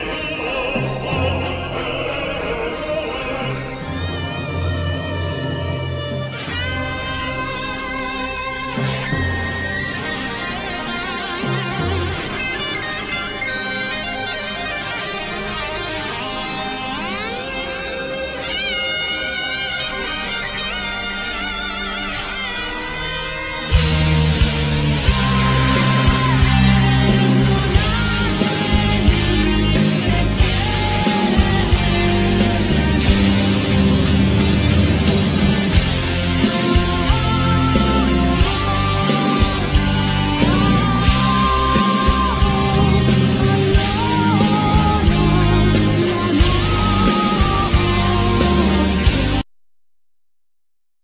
String Quintet:
Keyboard,Orchestrations
Vocals
Drum,Programming